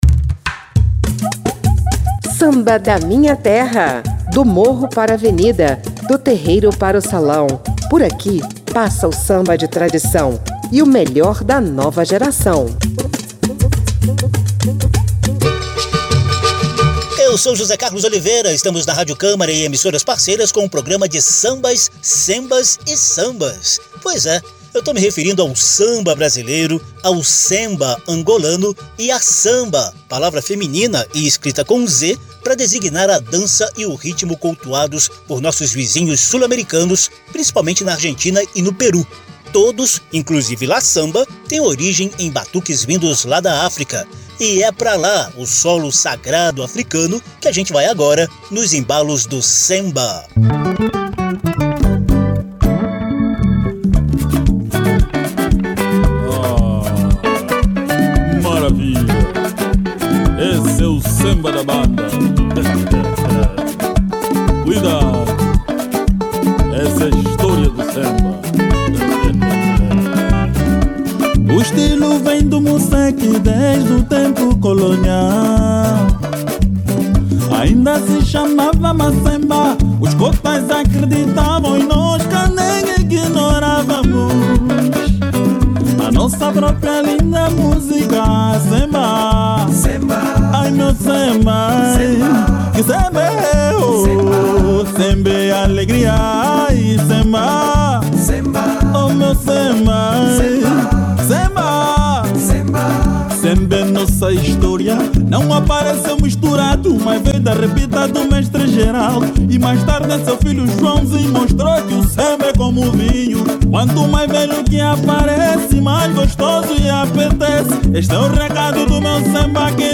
Samba da Minha Terra terá a visita de outros dois ritmos: o semba angolano e “la zamba” argentina e peruana. Em comum, todos têm origem em batuques africanos, inclusive “la zamba”, que já foi declarada patrimônio cultural da Argentina, mas tem origem na zamacueca dos negros peruanos.